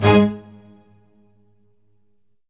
ORCHHIT G00R.wav